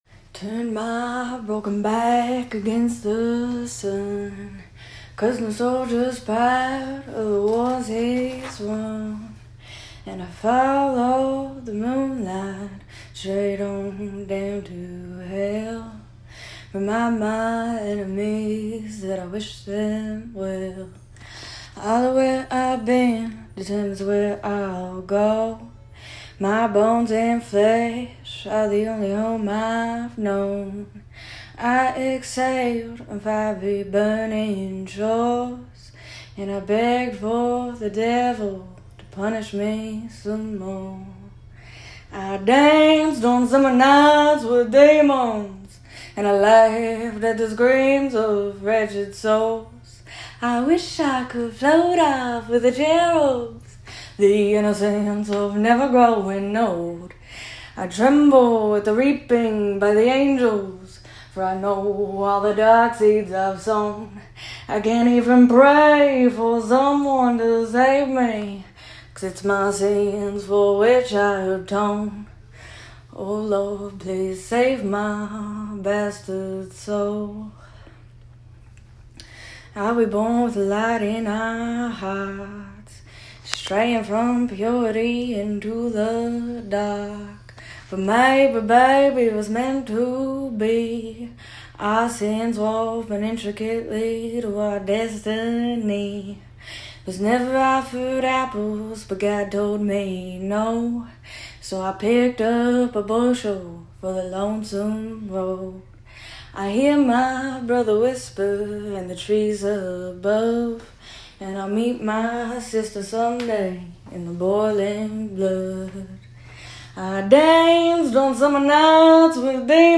The song has a lot of southern gothic vibes to it, it’s very intense lyrically, and I want the song behind it to match. The sample audio is poor, I recorded it in the work bathroom. I’m not the greatest vocalist, so some mixing on that would be important to me